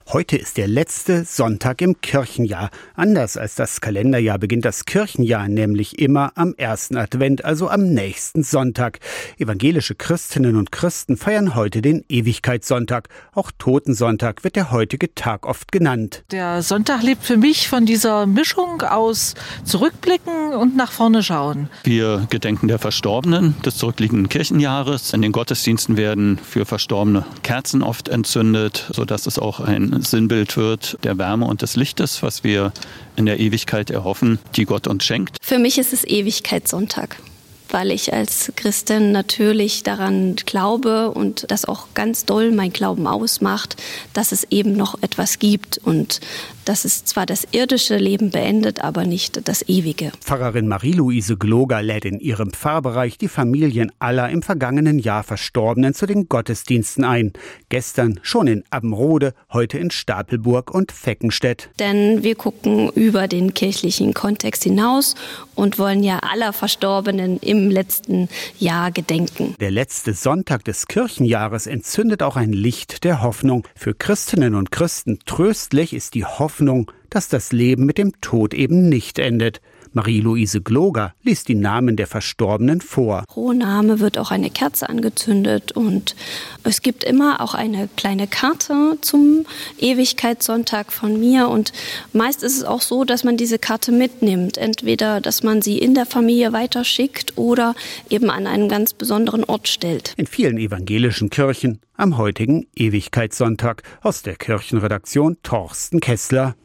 iad-radio-saw-ewigkeitssonntag-der-tod-ist-nicht-das-ende-43953.mp3